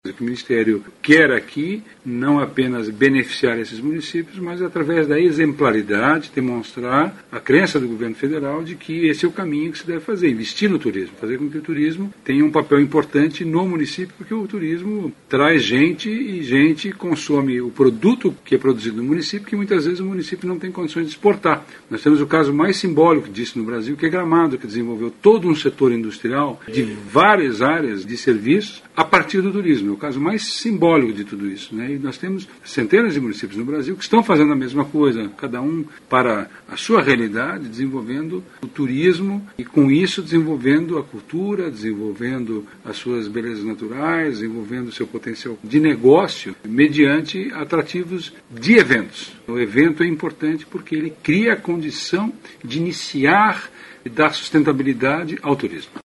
aqui e ouça declaração em que o secretário Vinícius Lummertz aponta benefícios do apoio a eventos.